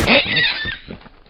PixelPerfectionCE/assets/minecraft/sounds/mob/horse/hit3.ogg at mc116